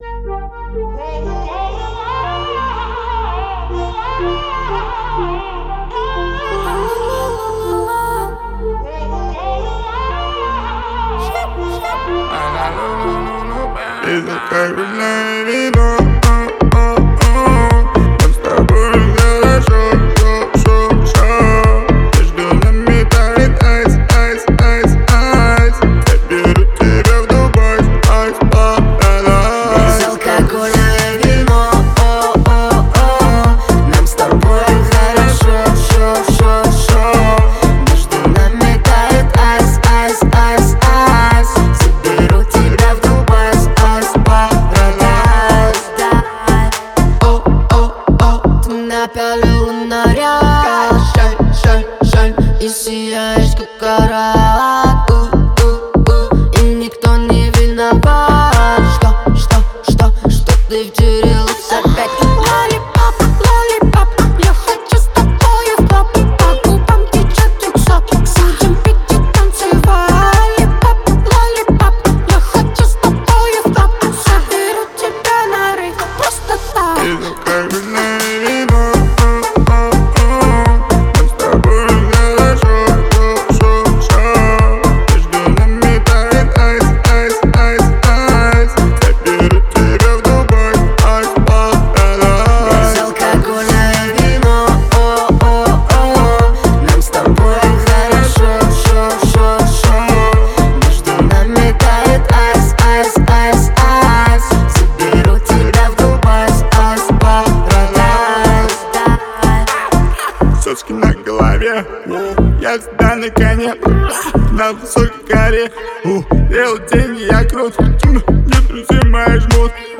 выполненная в жанре поп-рэп.